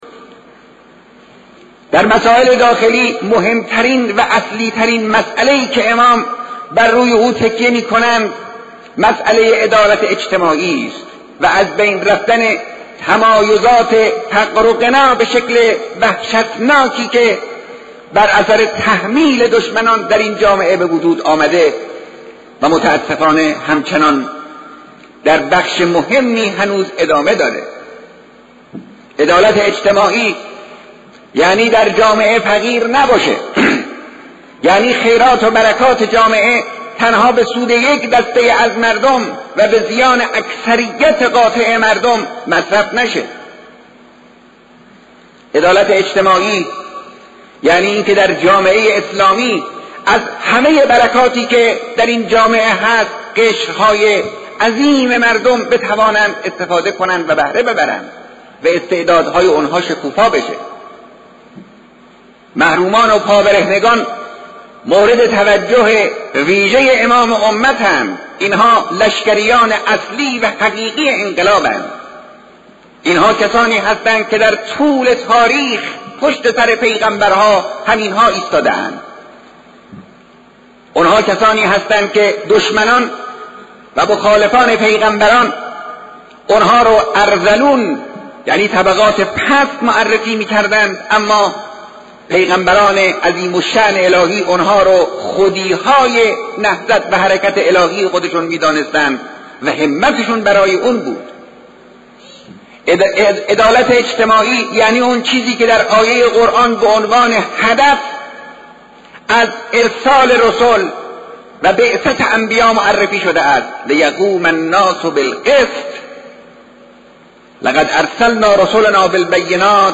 قسمت مهمی از سخنرانی بی نظیر و شنیده نشده از نخستین خطبه آیت الله خامنه ای پس از پذیرش قطعنامه۵۹۸ +صوت